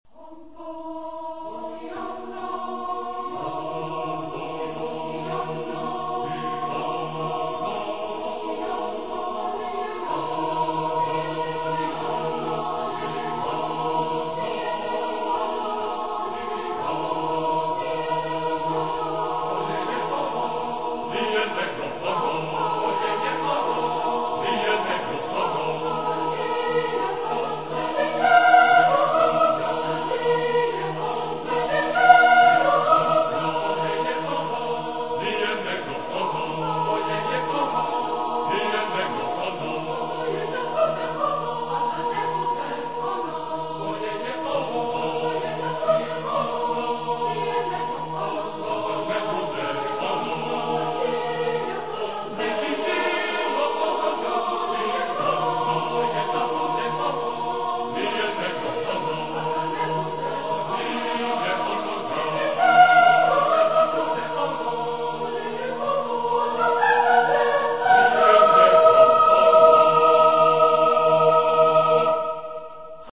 Genre-Style-Form: Secular ; Humorous ; Polyphony
Mood of the piece: ironic ; cheerful
Type of Choir: SSAATTB  (7 mixed voices )